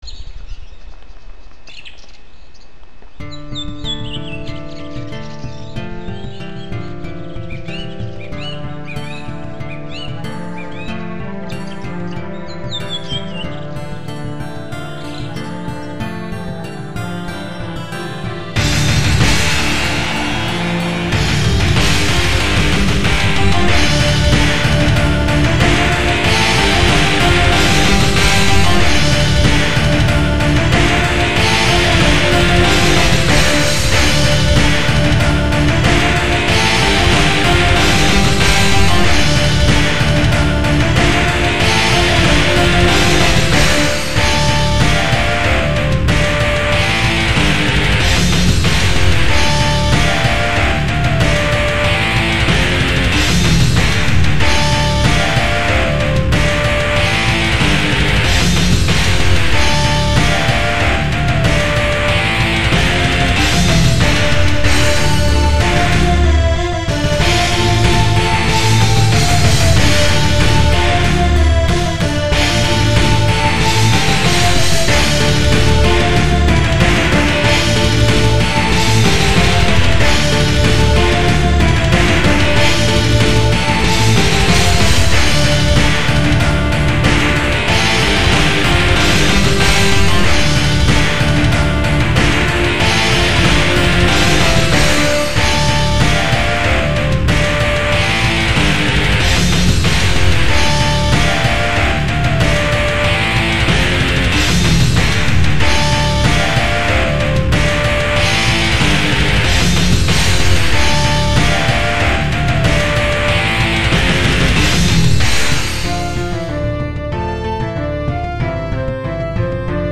Pagan metal